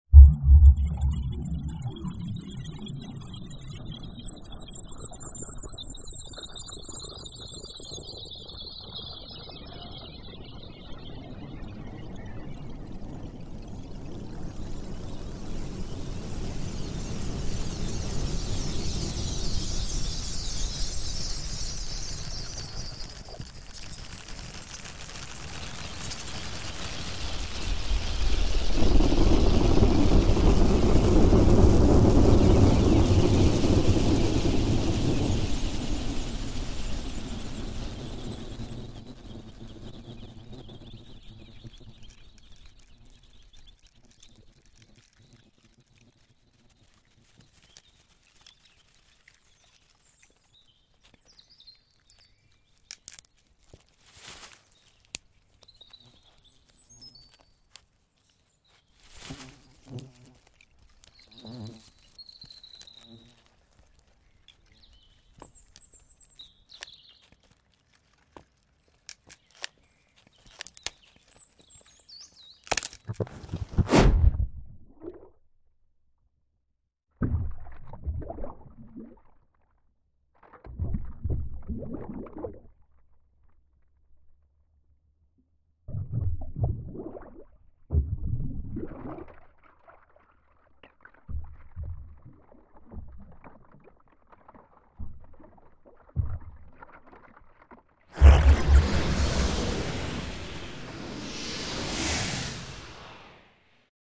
composition for tape (1999)